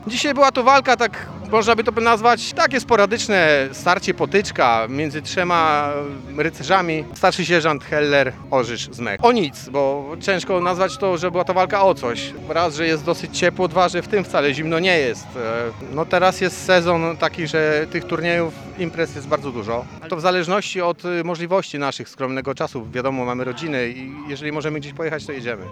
– Podczas turnieju zaprezentowała się grupa rekonstrukcyjna działająca od 2010 roku przy 15 GBZ – mówi jeden z rekonstruktorów.